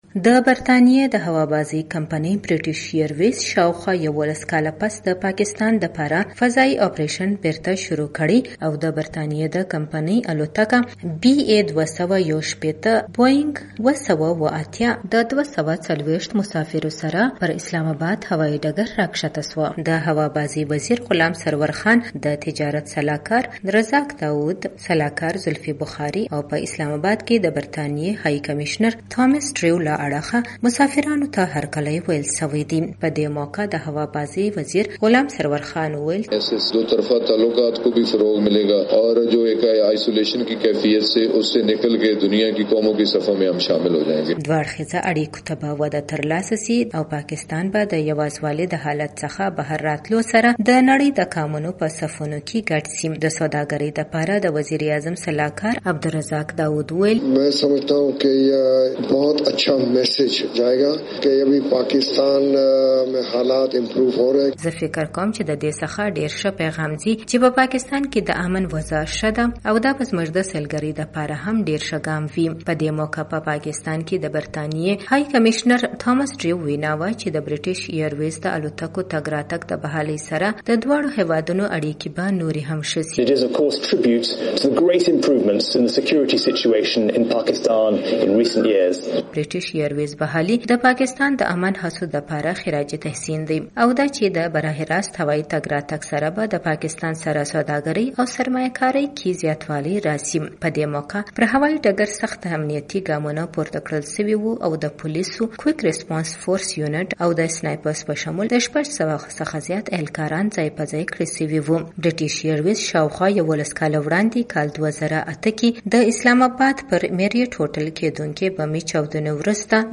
رپورټ: